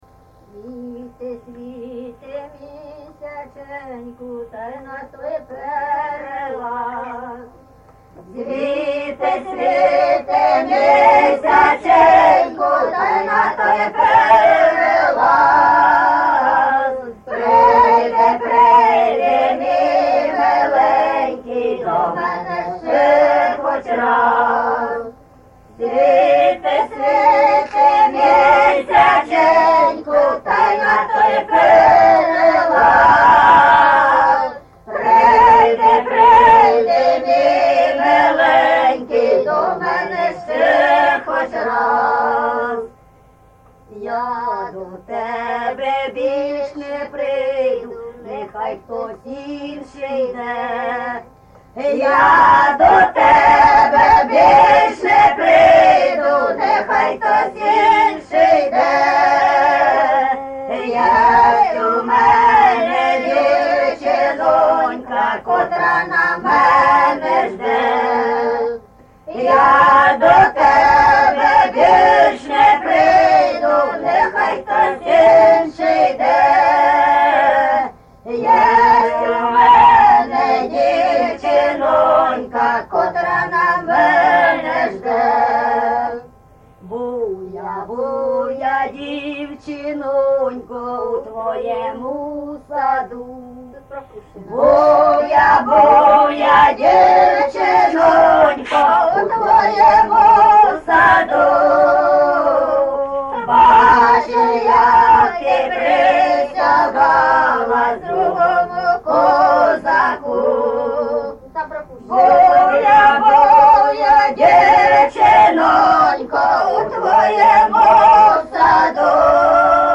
Місце записус. Лука, Лохвицький (Миргородський) район, Полтавська обл., Україна, Полтавщина